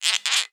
Scratch_v2_wav.wav